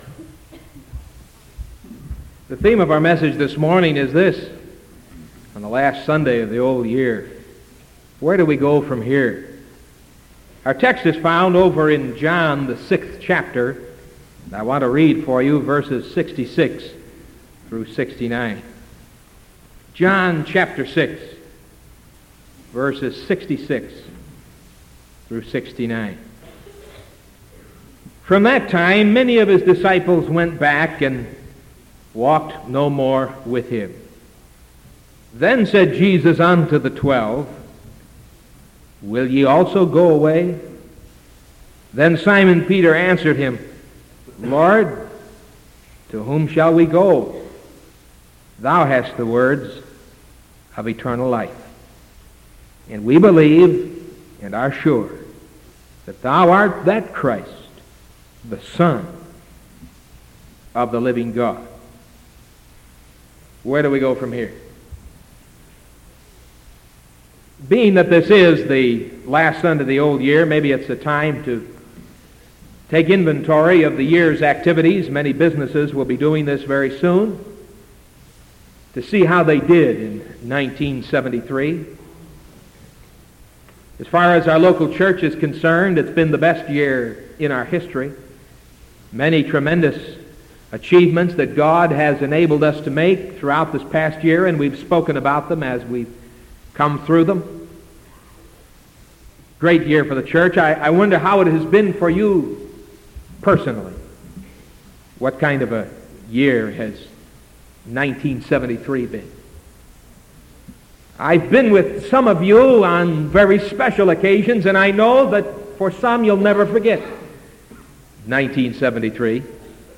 Sermon from December 30th 1973 AM